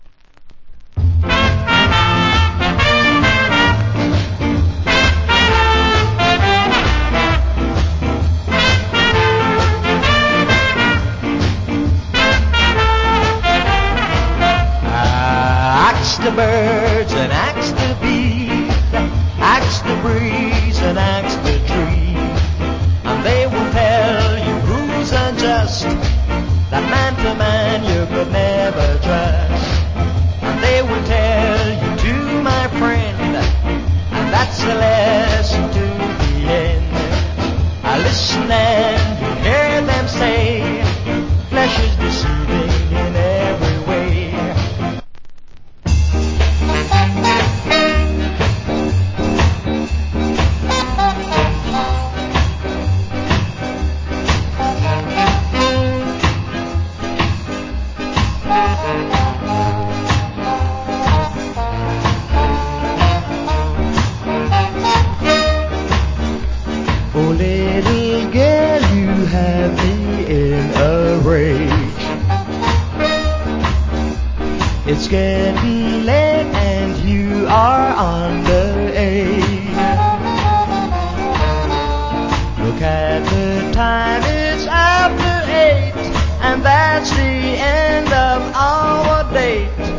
Wiched Ska Vocal.